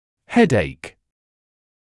[‘hedeɪk][‘хэдэйк]головная боль